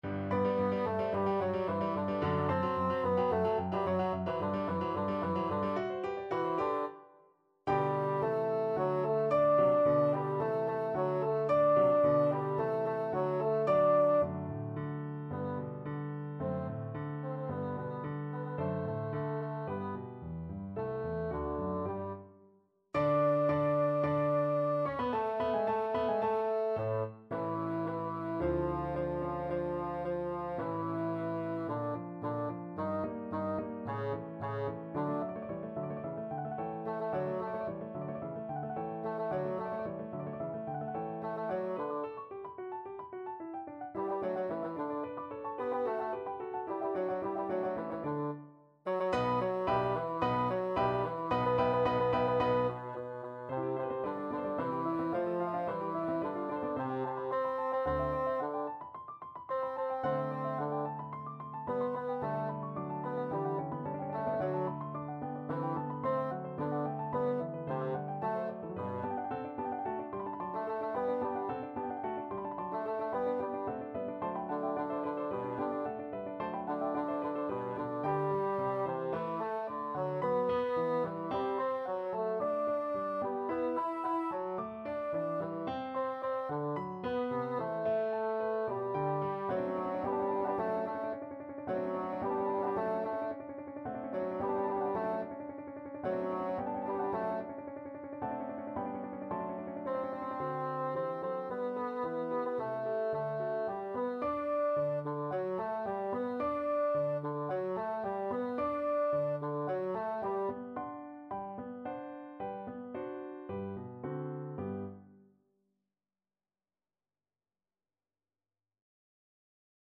Classical Mozart, Wolfgang Amadeus Ah! pieta Signori miei from Don Giovanni Bassoon version
G major (Sounding Pitch) (View more G major Music for Bassoon )
4/4 (View more 4/4 Music)
Allegro assai =220 (View more music marked Allegro)
Bassoon  (View more Intermediate Bassoon Music)
Classical (View more Classical Bassoon Music)